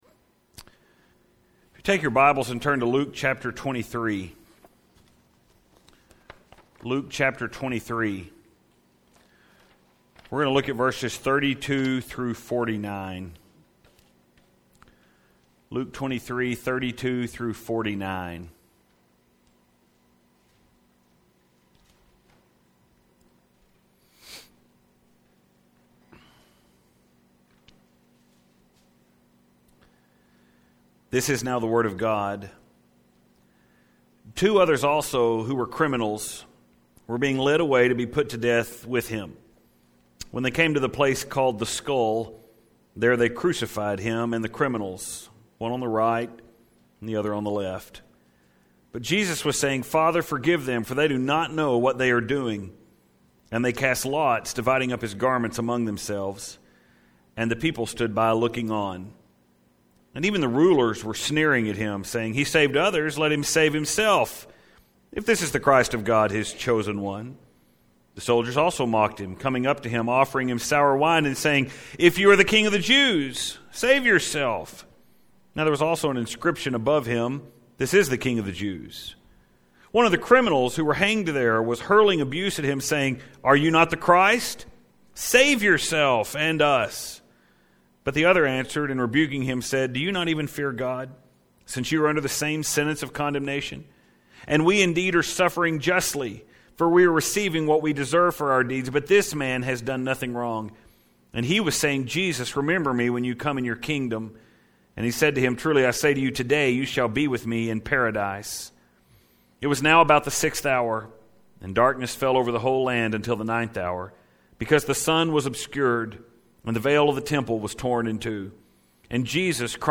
This morning we enter a study that has such weight and glory That the task of preaching it is really quite overwhelming.